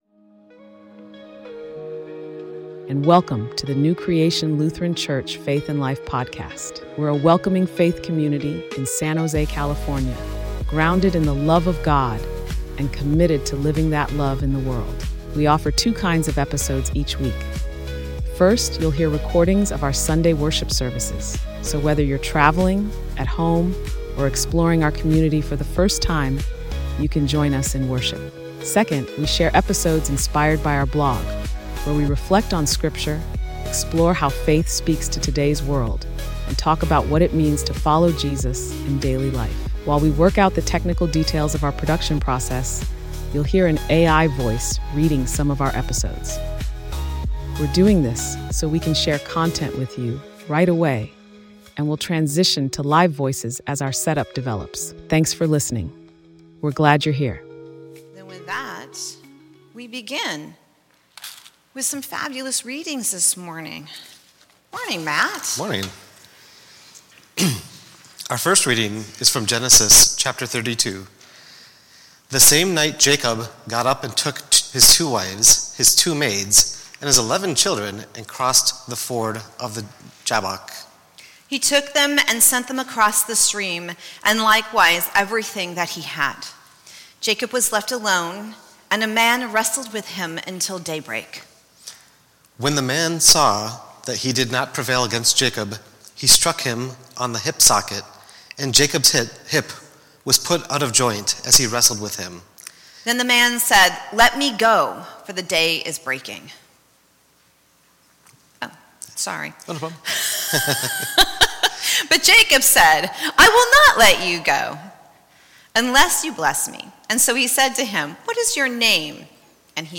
Sermon: Persistent Prayer and the Blessing of Faith